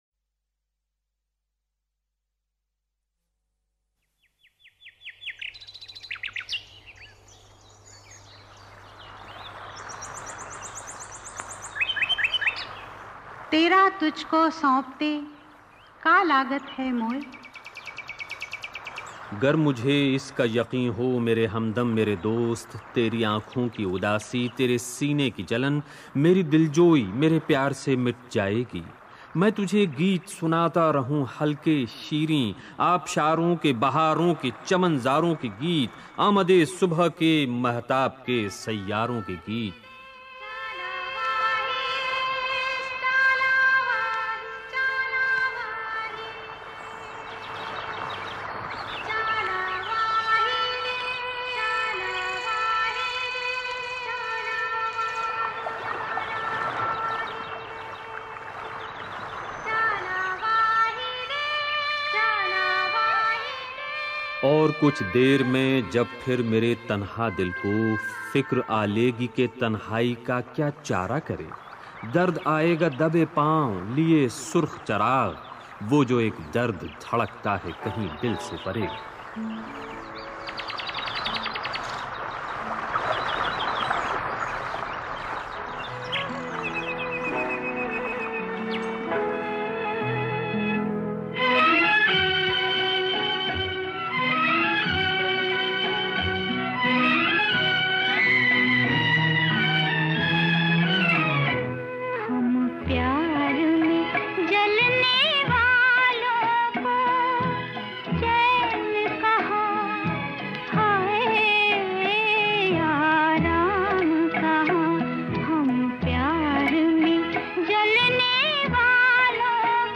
कभी मोहम्मद रफी के साथ मनमुटाव होने पर तीन साल उनके साथ गाना नहीं गाया लता जी ने. जानिए उनकी कहानी उन्हीं की ज़ुबानी.